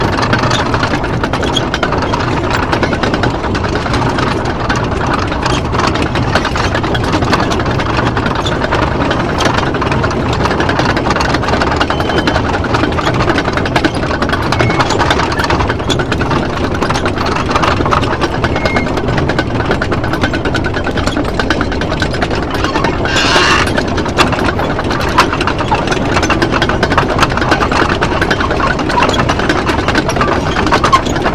tank-tracks-1.ogg